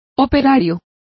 Complete with pronunciation of the translation of workmen.